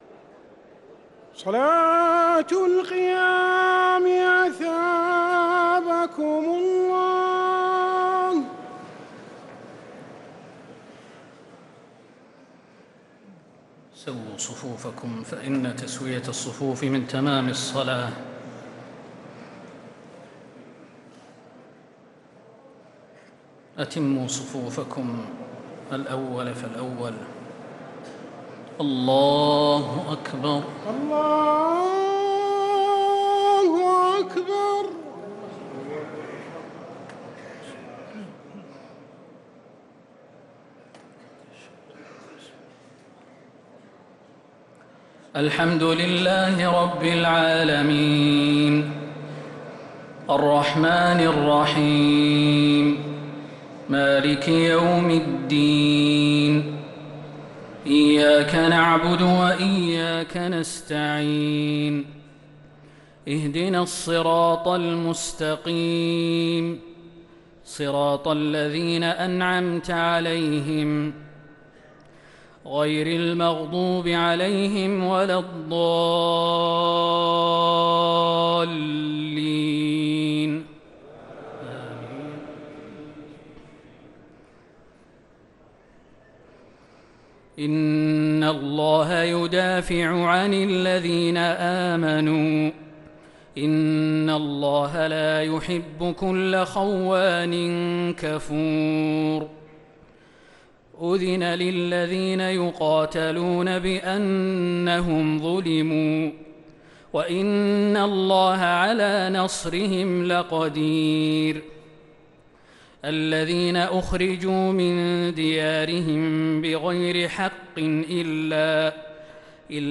تراويح ليلة 22 رمضان 1446هـ من سورتي الحج (38-78) و المؤمنون (1-50) | Taraweeh 22nd night Ramadan 1446H Surah Al-Hajj and Al-Muminoon > تراويح الحرم النبوي عام 1446 🕌 > التراويح - تلاوات الحرمين